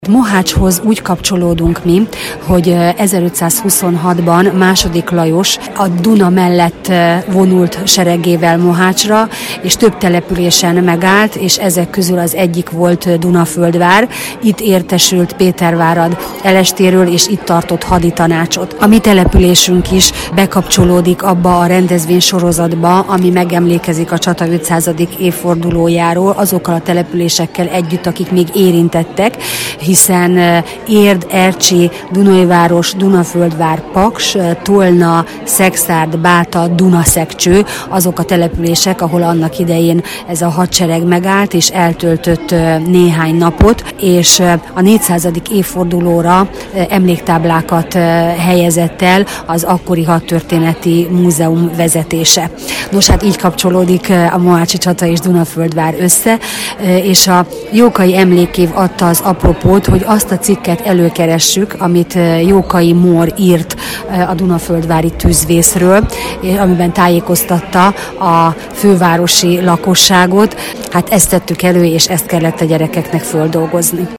Paks FM. Hírek posztjai